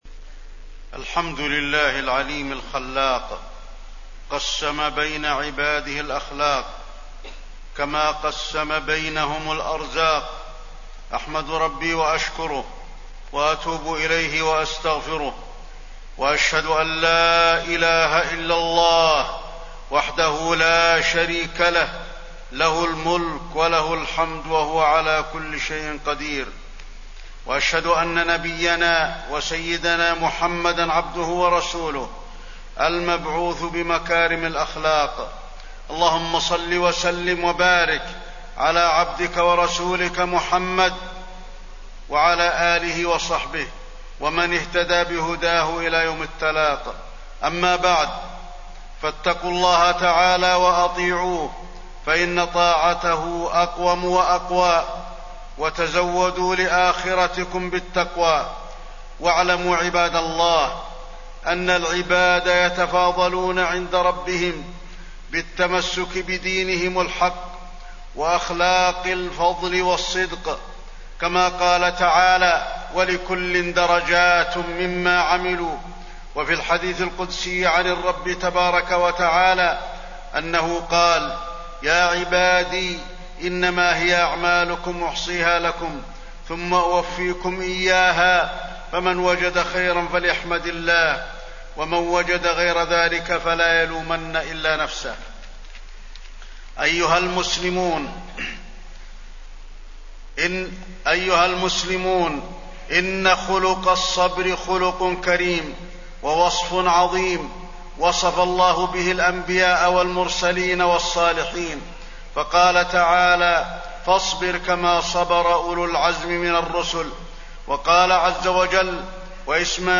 تاريخ النشر ٤ ذو القعدة ١٤٣٠ هـ المكان: المسجد النبوي الشيخ: فضيلة الشيخ د. علي بن عبدالرحمن الحذيفي فضيلة الشيخ د. علي بن عبدالرحمن الحذيفي خلق الصبر